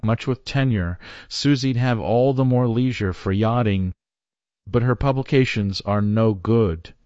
text-to-speech voice-cloning